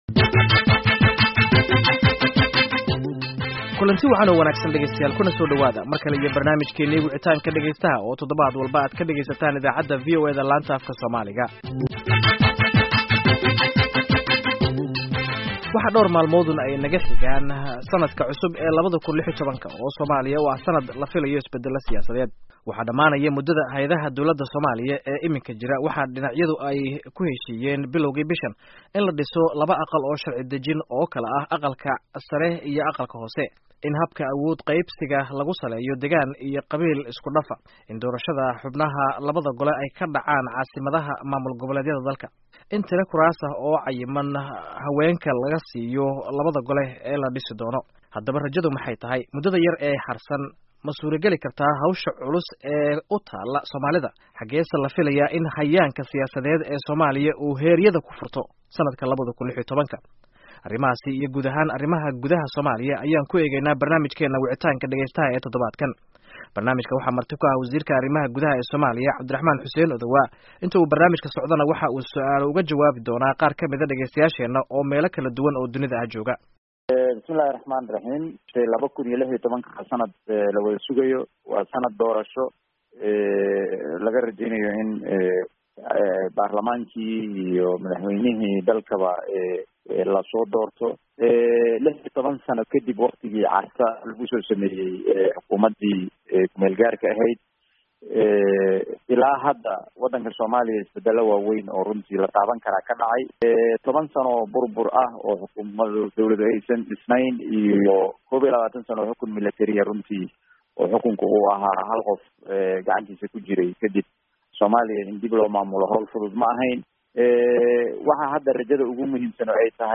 Barnaamijka wicitaanka dhageystaha waxaanu ku eegeynaa arrimaha Soomliya iyo sanadka soo socda ee 2016ka. Waxaa barnaamijka marti ku ah Wasiir Cabdiraxmaan Odowaa